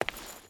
Footsteps
Stone Chain Walk 5.wav